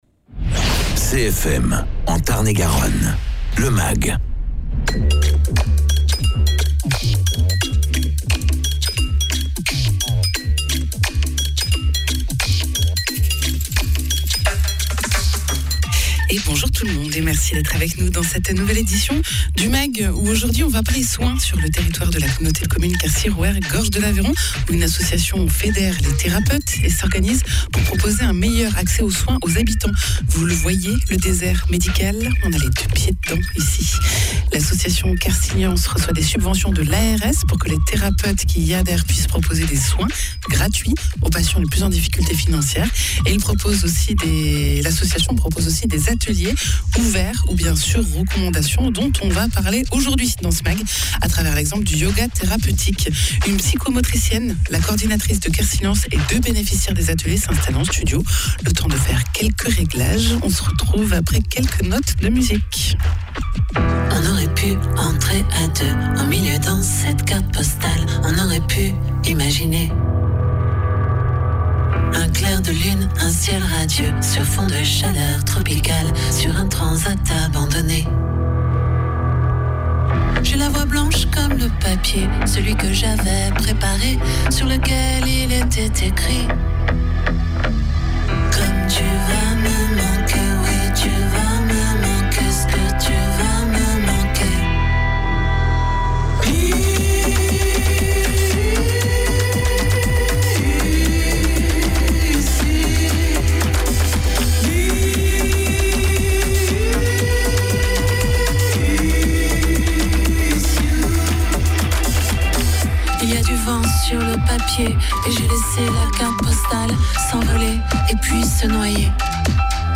Patrimoine reportage sur les charpentes de l’église de Caylus